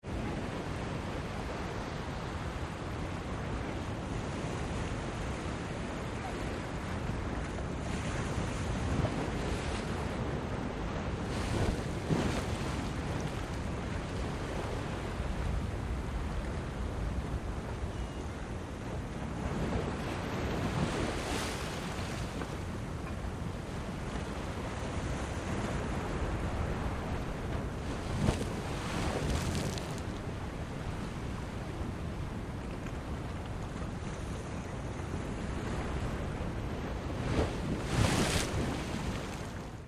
MAR RUIDO SONIDO EFECTO
Ambient sound effects
mar_ruido_sonido_efecto.mp3